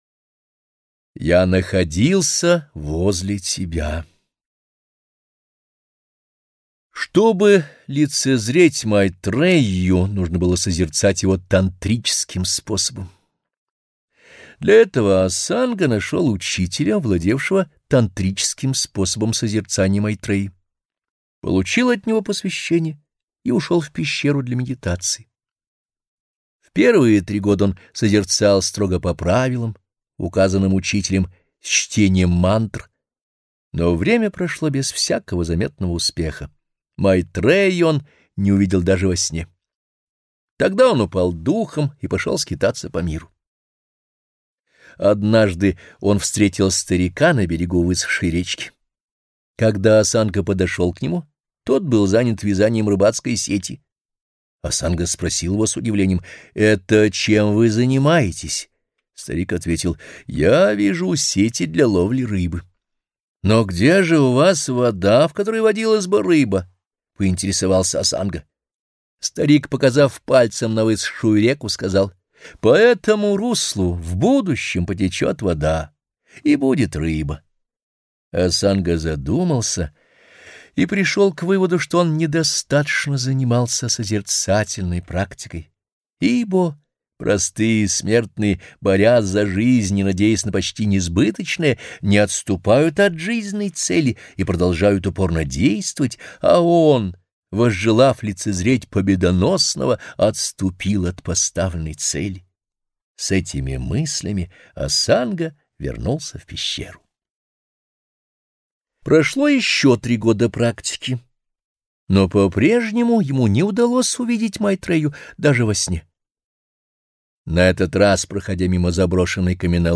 Аудиокнига Притчи народов мира. Буддийские притчи | Библиотека аудиокниг